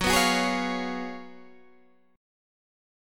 GbM7sus2sus4 chord